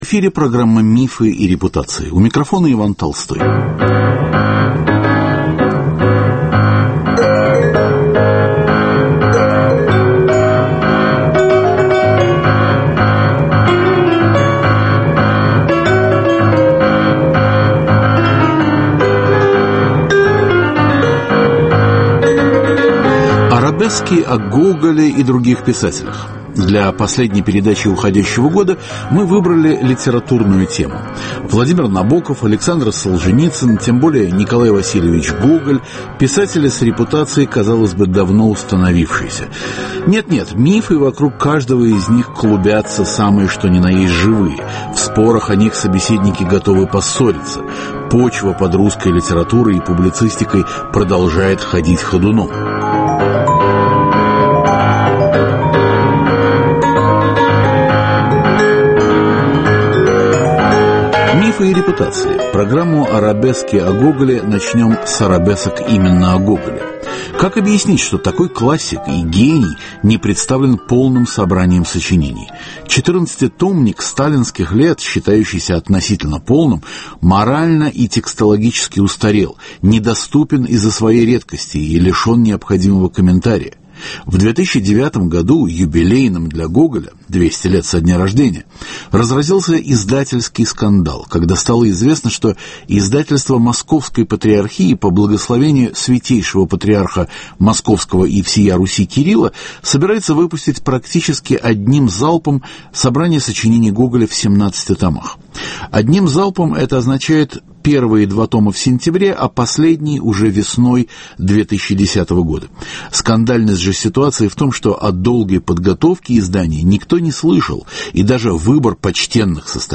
Разговор о мифах и репутациях литературных классиков.